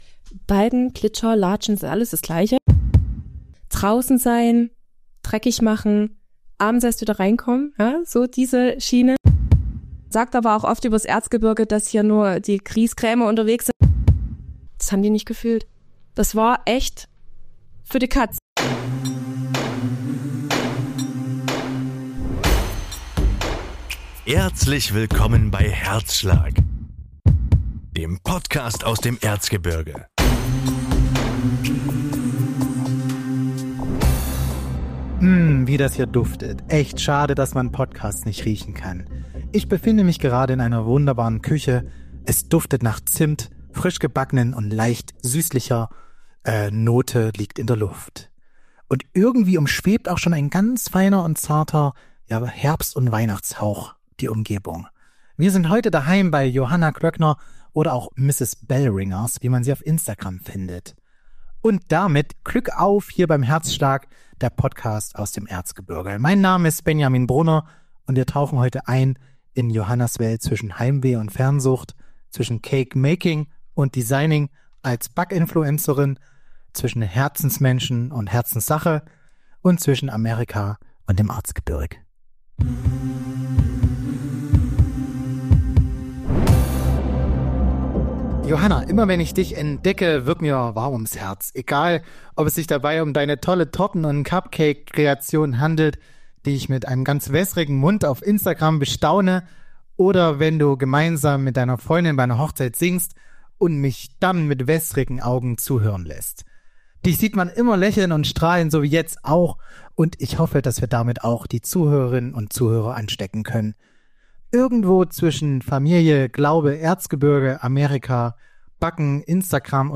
Achtung: Freunde von ASMR – Autonomous Sensory Meridian Response (unabhängige sensorische Meridianreaktion) kommen heute richtig auf ihre Kosten.